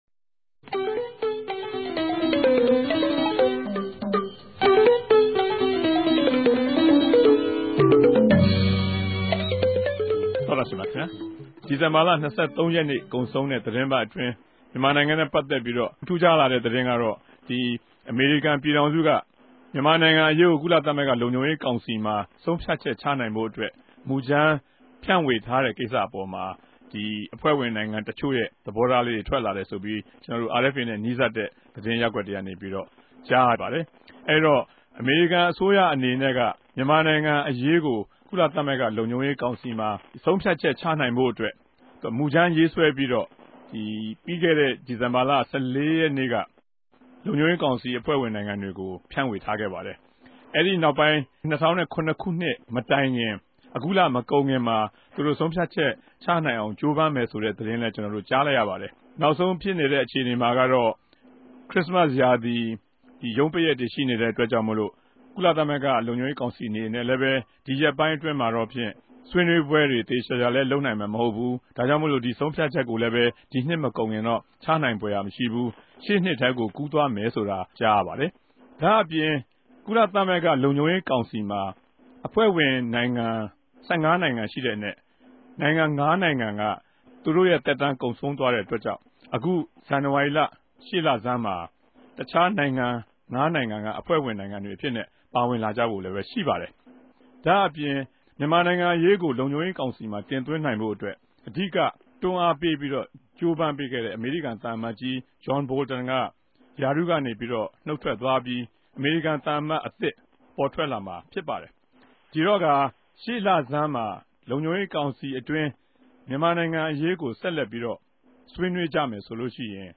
ဝၝရြင်တန်္ဘမိြႚ RFA စတူဒီယိုထဲကနေ